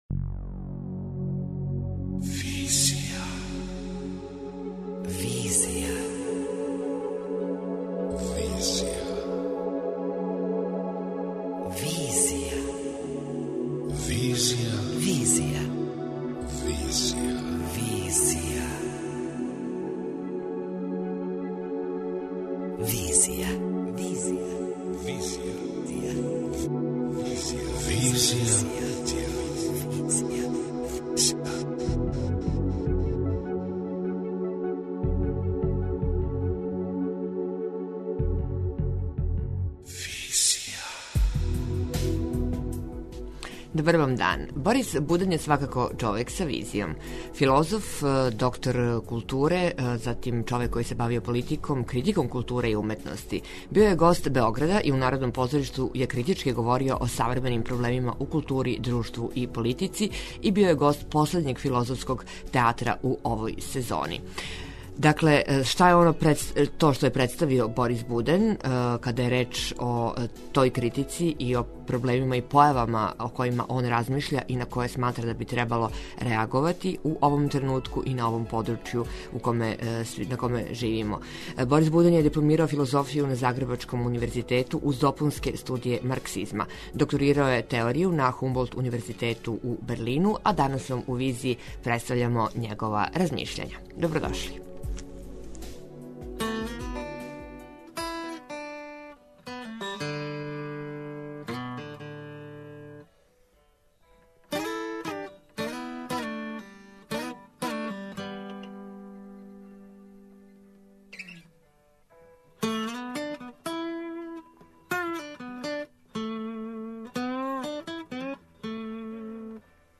Борис Буден је био гост Београда и у Народном позоришту је критички говорио о савременим проблемима у култури, друштву, политици.
Све то у занимљивом разговору у оквиру филозофског театра где су до сада гостовале звезде светске теорије, филозофије и активизма.